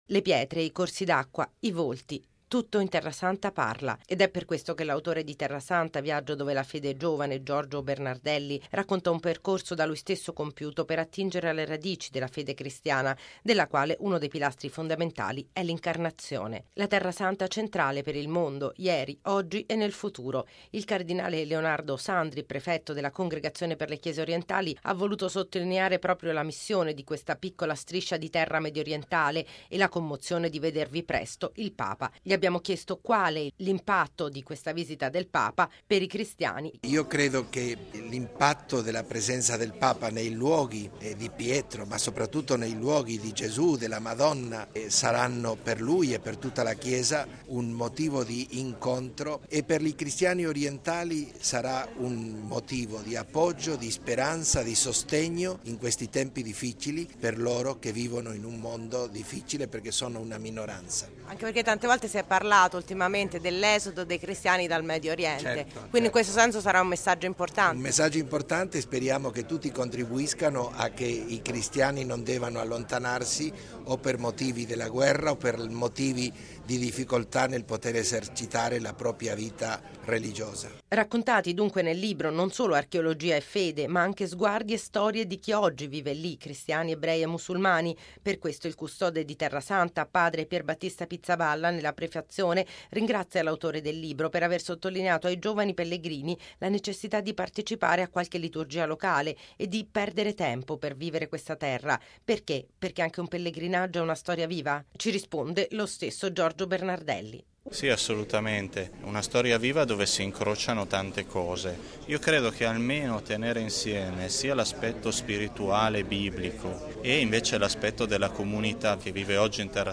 Alla presentazione del libro, ieri pomeriggio presso la nostra emittente, è intervenuto tra gli altri il cardinale Leonardo Sandri, prefetto della Congregazione per le Chiese Orientali.
Al cardinale Leonardo Sandri abbiamo chiesto quali il significato, l’impatto per i cristiani e in particolare per i cristiani delle Chiese Orientali: